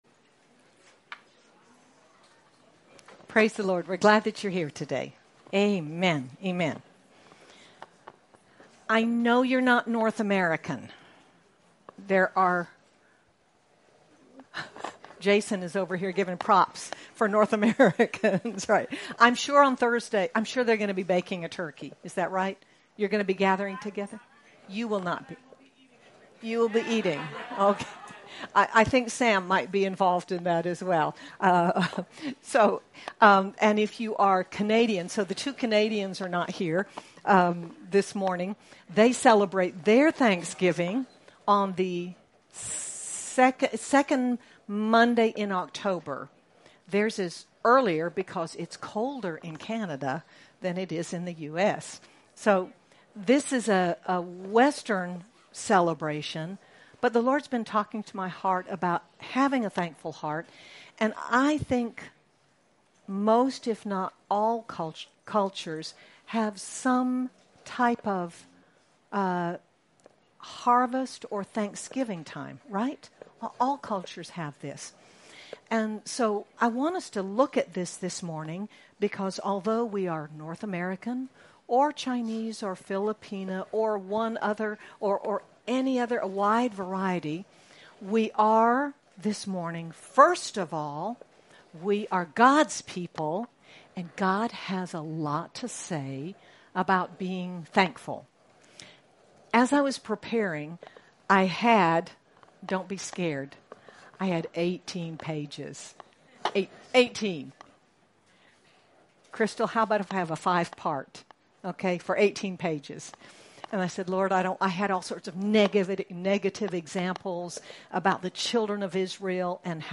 Nov 24, 2025 Give Thanks MP3 SUBSCRIBE on iTunes(Podcast) Notes Discussion We so often overlook or take for granted the simple command to give thanks, but God’s Word gives us many examples and reasons to live our lives with overflowing thankfulness to Him. Sermon by